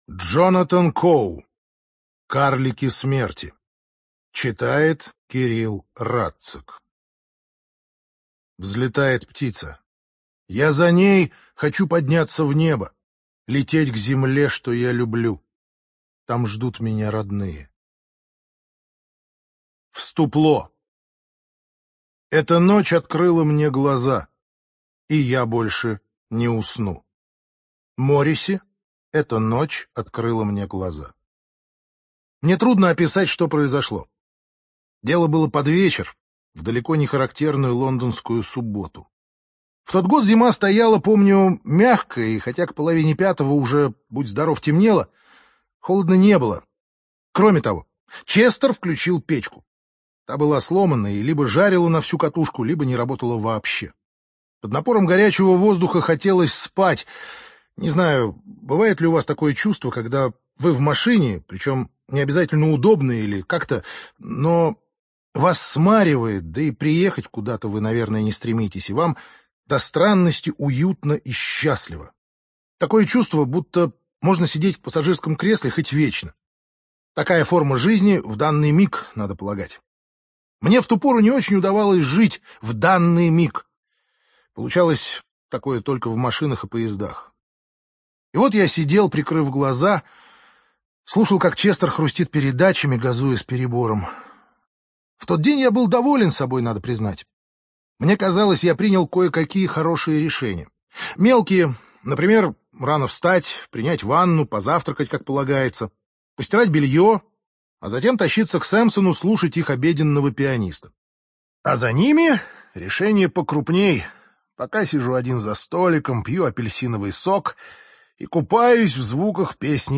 ЖанрСовременная проза